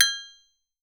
AFRCN AGOGOS